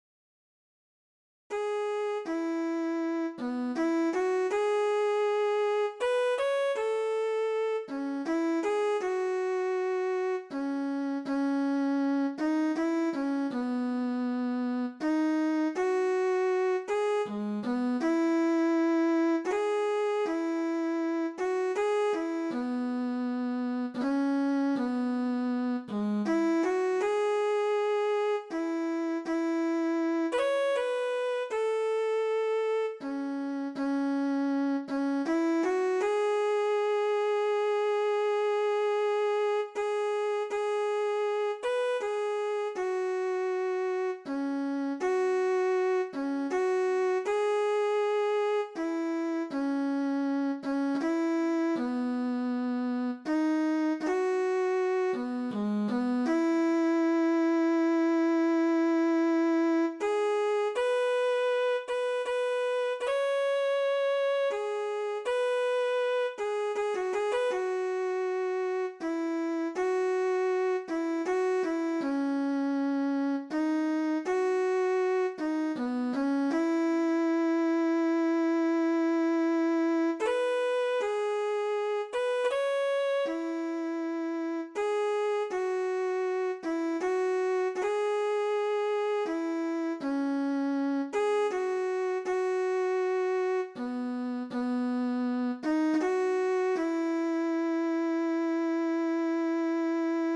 THIẾU NHI CA